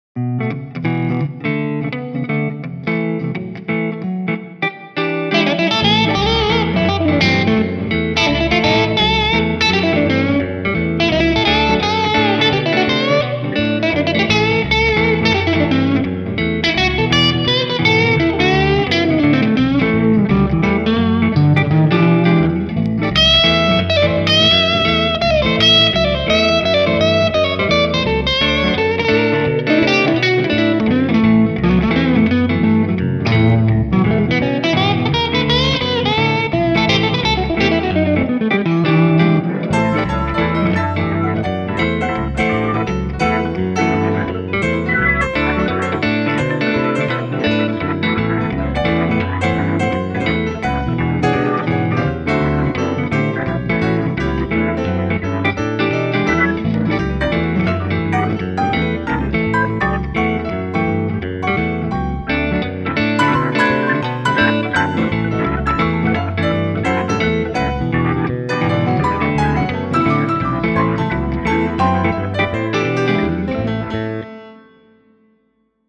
Mellow or heavy, from jazz to rock.
Solo